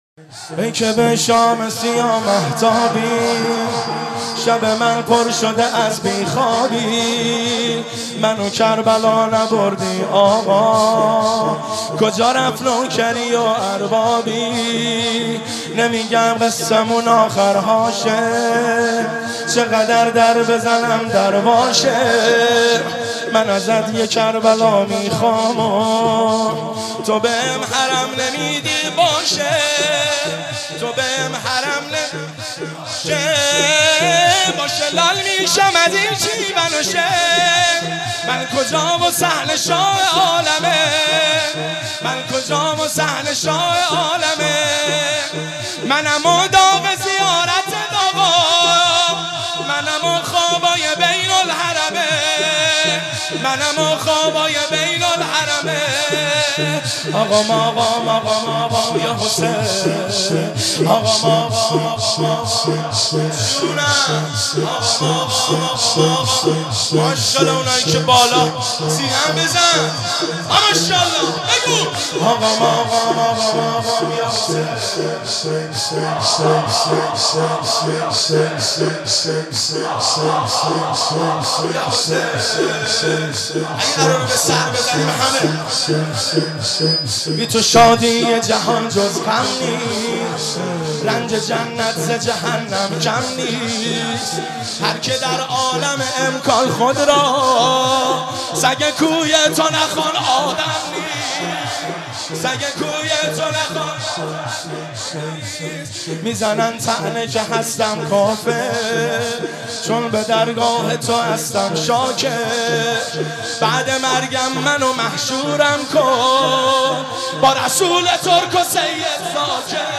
مداحی ای که به شام سیاه مهتابی(شور)
اجتماع مدافعان حرم زینبی(س) خرداد 1392